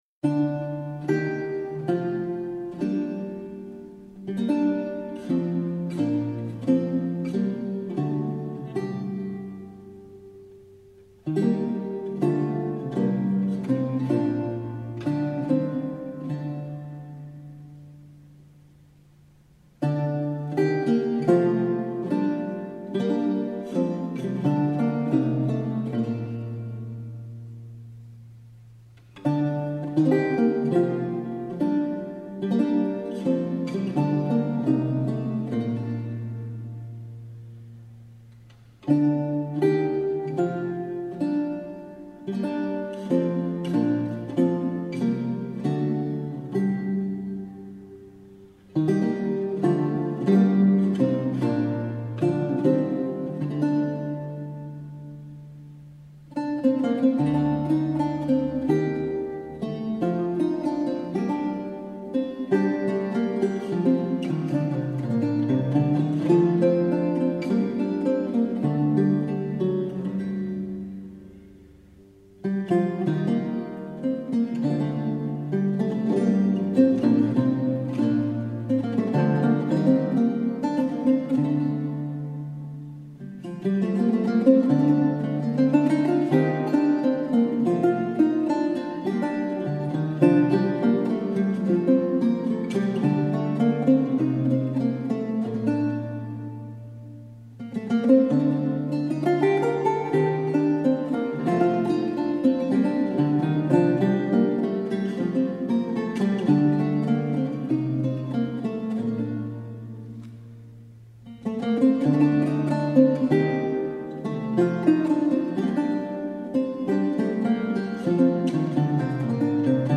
servi par la voix suave du contre-ténor
violes, luth, harpe